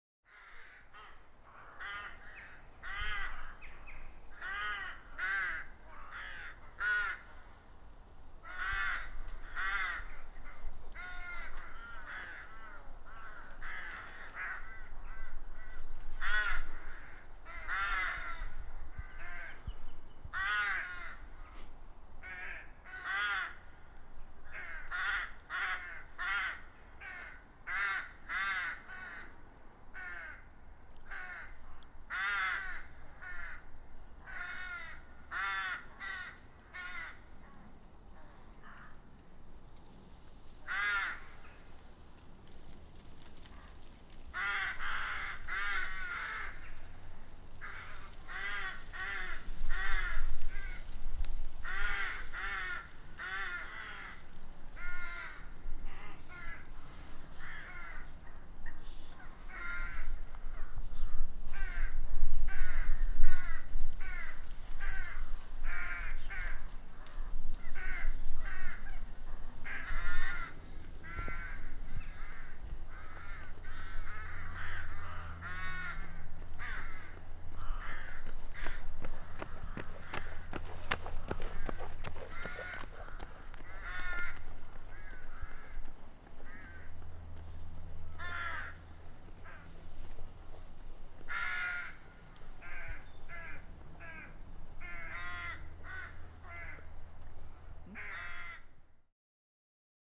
Crows nesting in the palace grounds
Crows nesting high in the trees in the ground of Blenheim Palace, being very vocal on a Sunday afternoon, as we also hear one of the regular joggers in the grounds going past us.